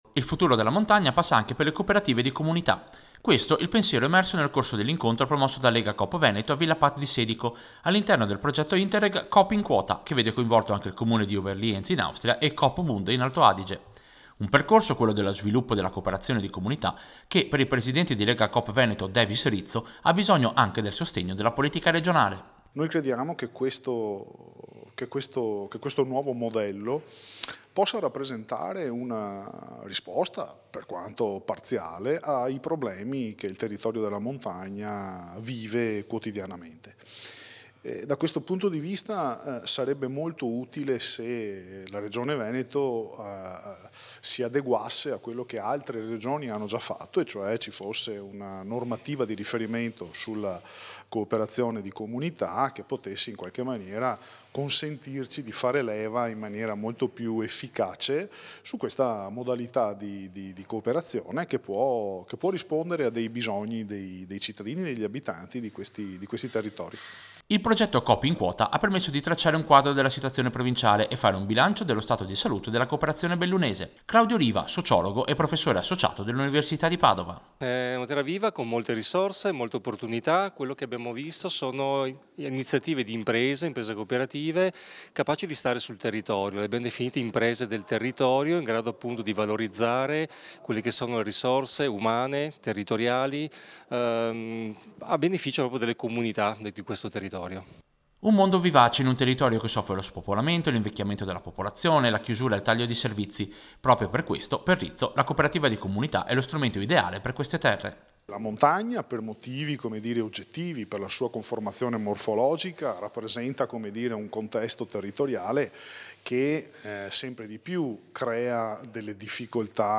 Servizio-Cooperare-in-quota-Legacoop.mp3